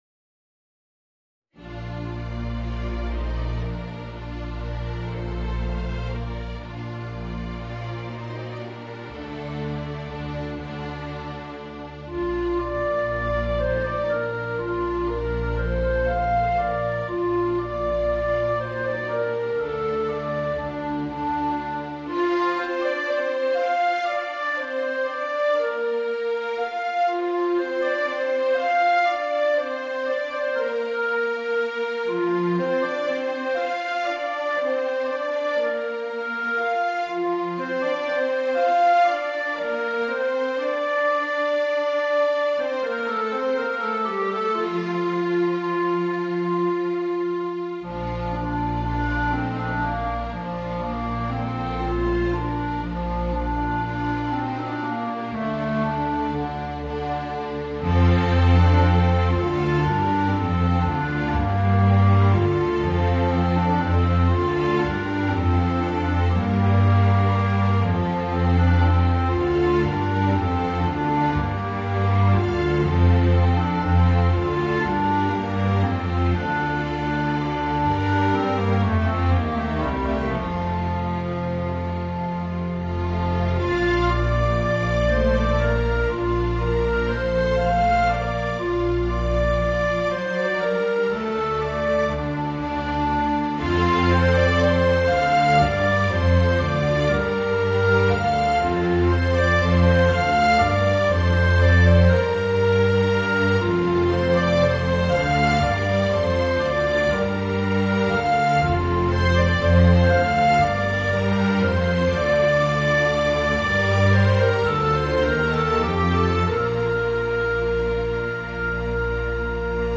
Voicing: Alto Saxophone and String Orchestra